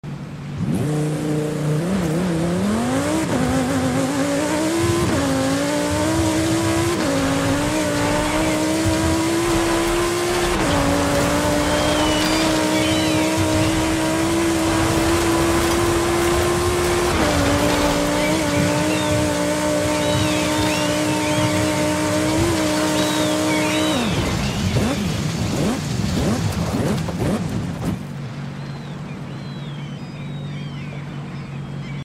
2023 Lamborghini Huracan Sterrato Off Road Sound Effects Free Download